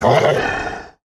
Minecraft Version Minecraft Version latest Latest Release | Latest Snapshot latest / assets / minecraft / sounds / mob / wolf / angry / death.ogg Compare With Compare With Latest Release | Latest Snapshot
death.ogg